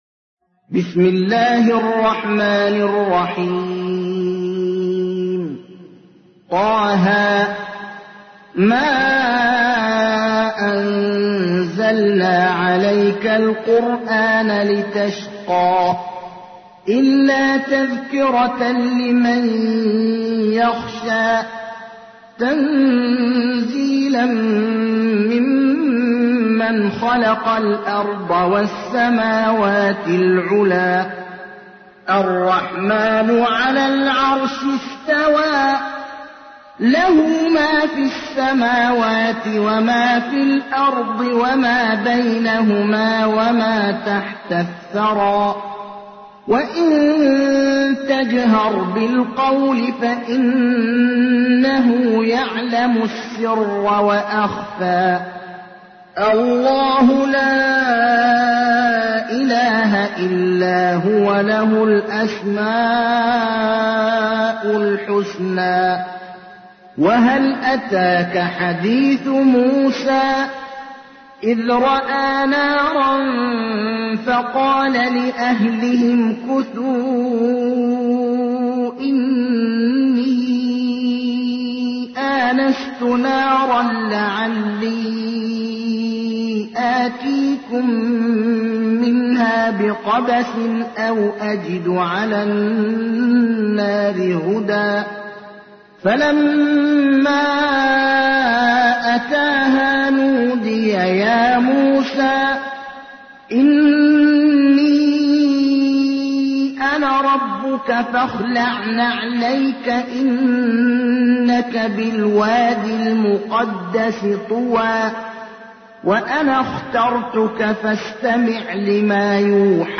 تحميل : 20. سورة طه / القارئ ابراهيم الأخضر / القرآن الكريم / موقع يا حسين